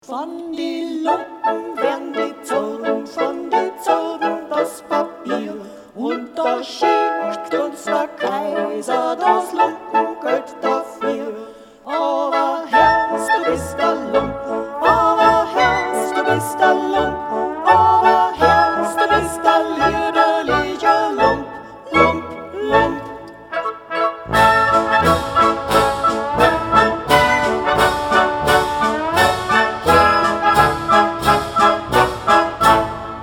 Xylophon
key: A-Dur